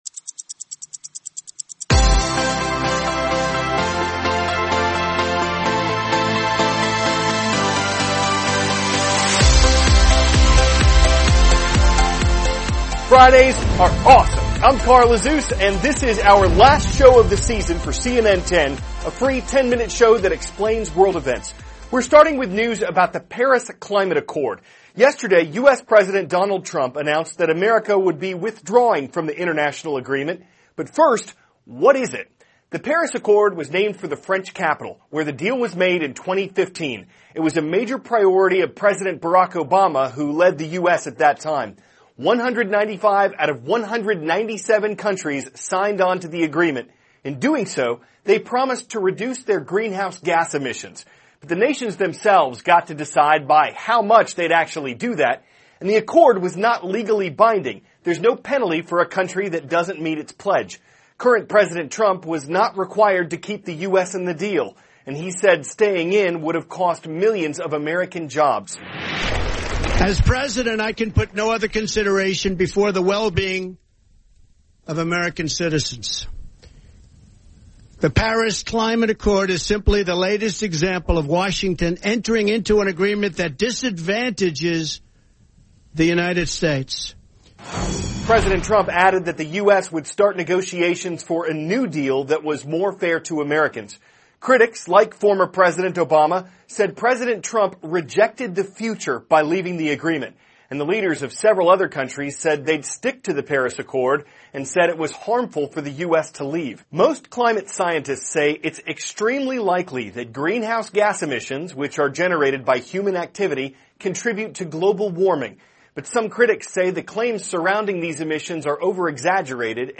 *** CARL AZUZ, cnn 10 ANCHOR: Fridays are awesome.